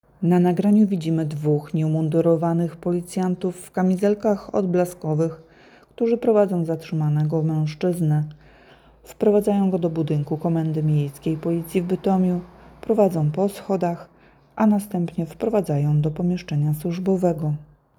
Nagranie audio Deskrypcja filmu